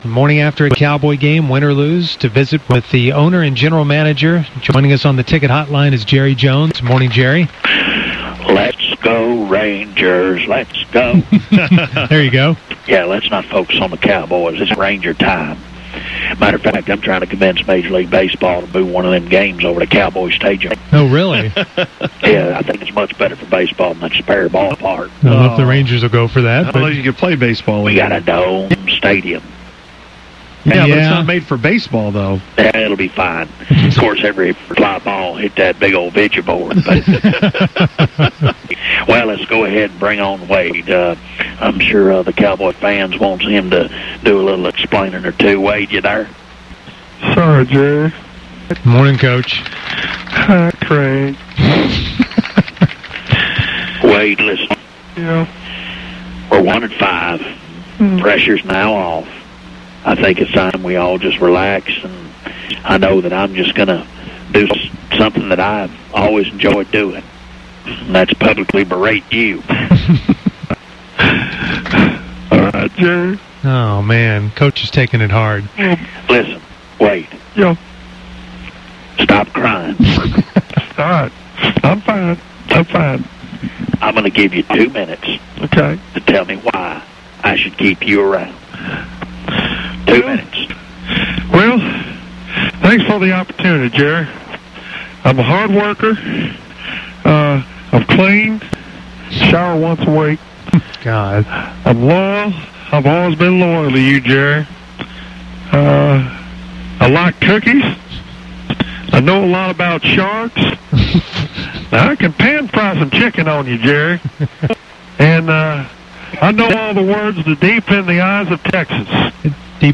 Jerry comes on going into the “Let’s Go Rangers” chant saying they need to play a game at his stagium. Then he brings on an almost crying Wade.
Then he takes a shot at one of his assistant coaches and tries a few sports quotes. Then it ends in a very interesting verbal debate.